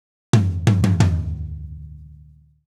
Drumset Fill 03.wav